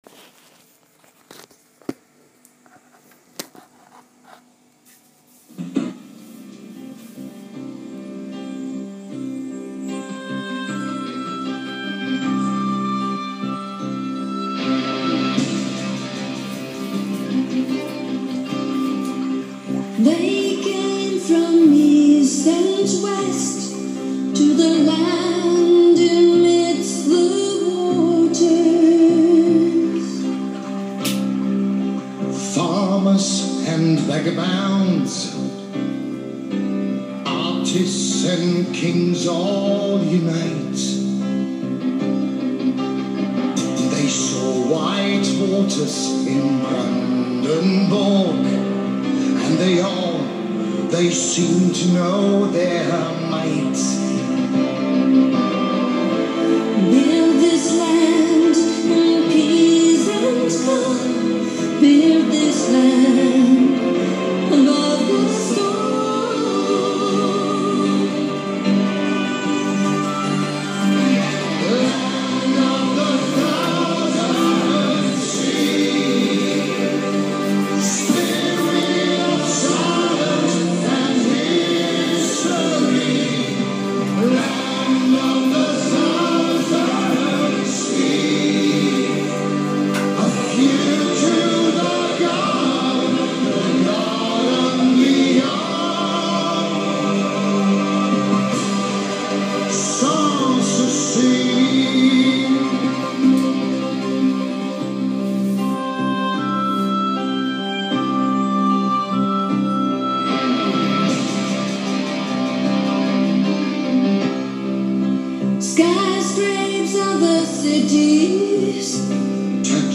Leider kann ich dazu nicht viel sagen, außer das der Song mal in den Nachtstunden auf irgendein Radiosender lief und ein Bekannter dies per Handy aufgenommen hat.
Eine Hymne auf „Brandenbourg“ ….